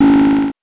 8 bits Elements
powerup_26.wav